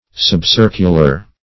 Subcircular \Sub*cir"cu*lar\, a. Nearly circular.